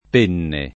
— pn. loc. con -e- aperta